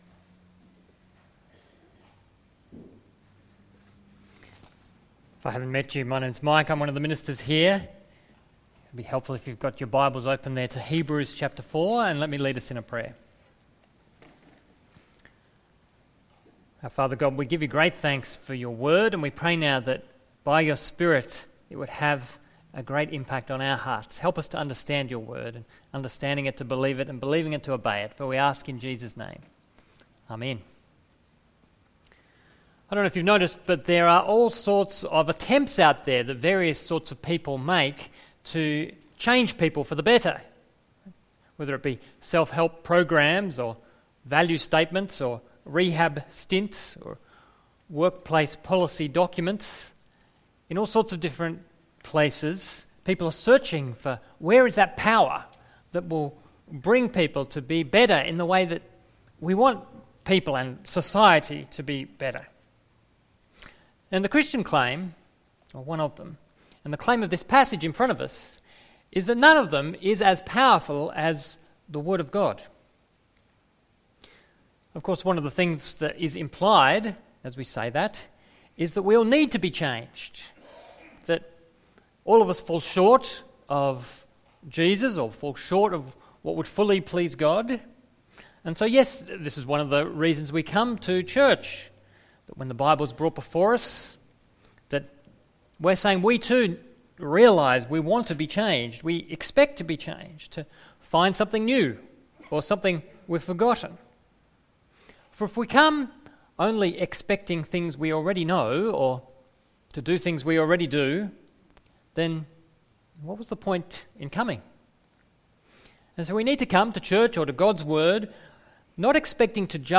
Sermons | St George's Magill Anglican Church